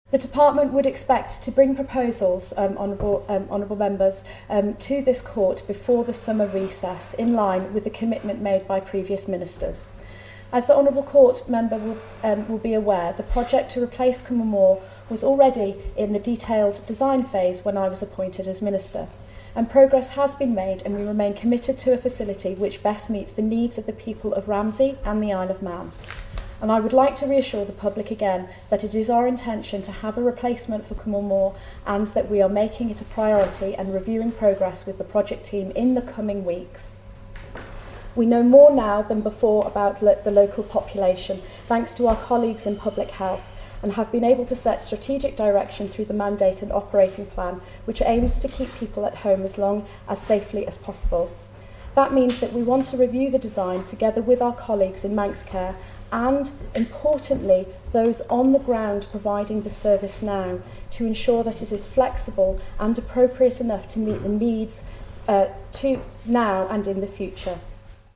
In the House of Keys today Ramsey MHK Lawrie Hooper asked Claire Christian for an update: